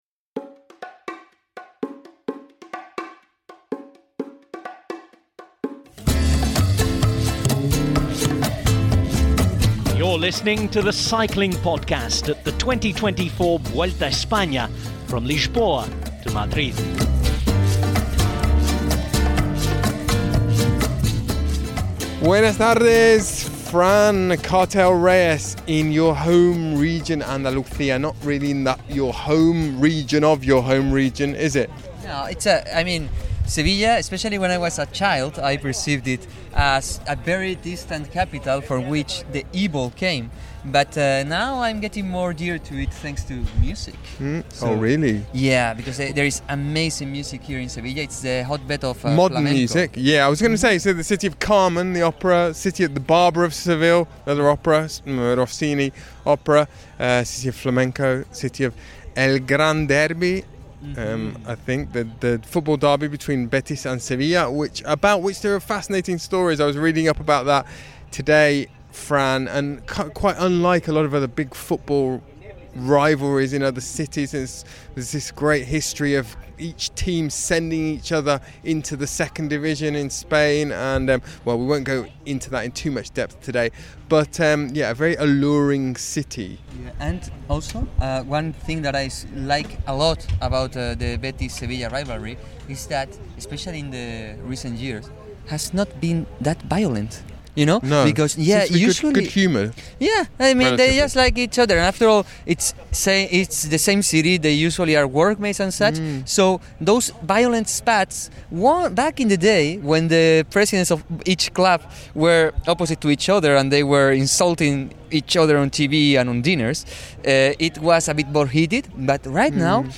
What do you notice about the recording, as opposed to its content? There’ll be analysis, interviews, wistful gazing and tepid takes from on the ground, in the thick of the action - and a return for both popular and unpopular features from previous editions!